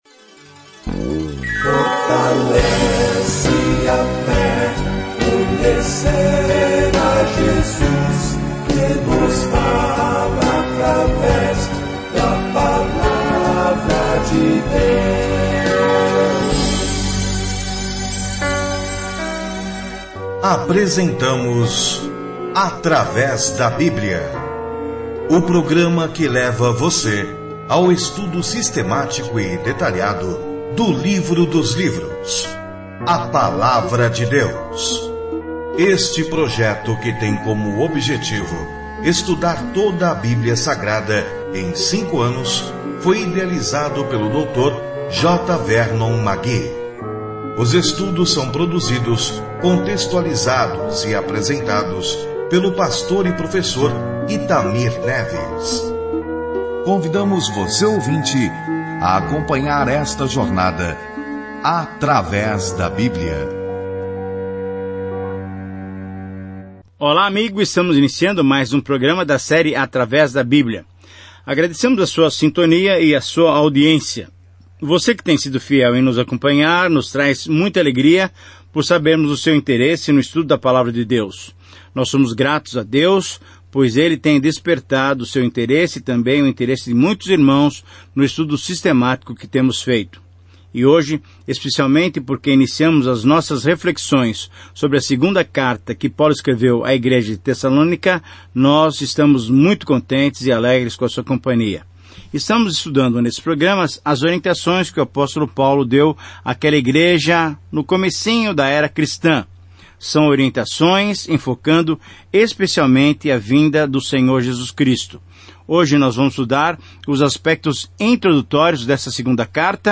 É o desafio desta segunda carta aos Tessalonicenses que nos lembra que Jesus está voltando para nós. Viaje diariamente por 2 Tessalonicenses enquanto ouve o estudo em áudio e lê versículos selecionados da palavra de Deus.